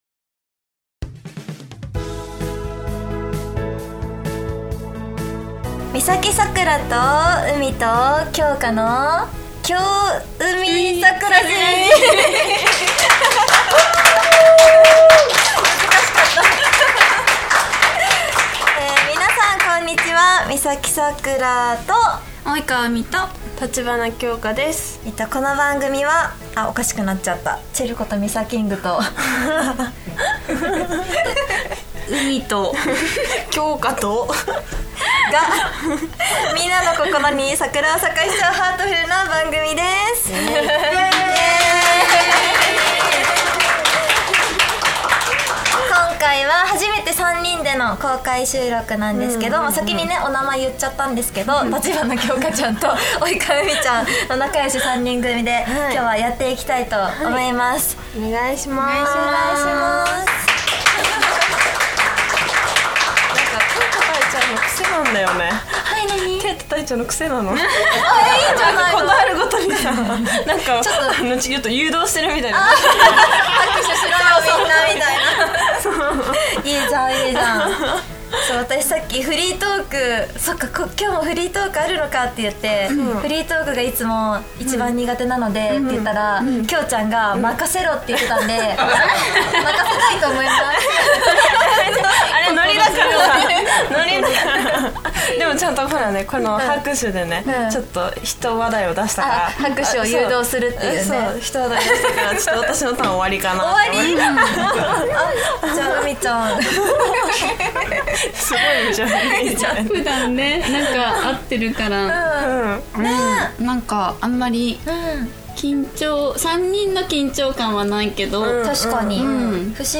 久しぶりの公開収録です！